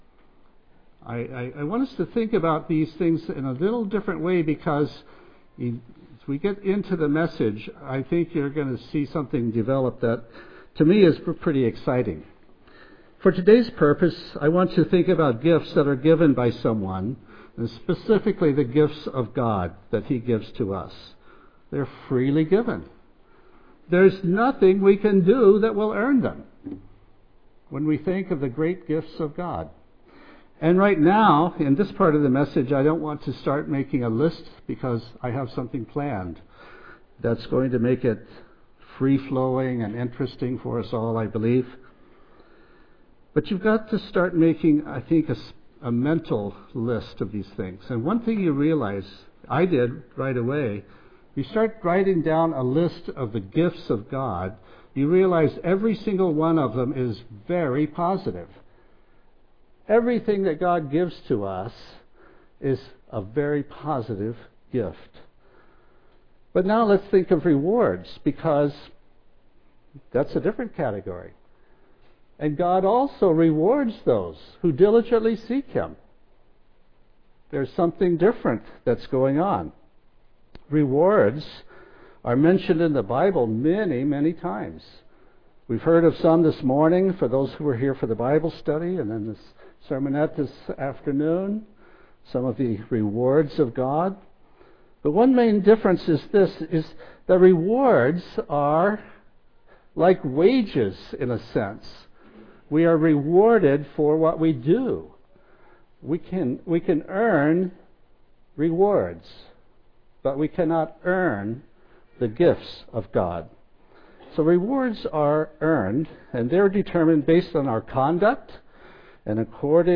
Given in Tacoma, WA
UCG Sermon Studying the bible?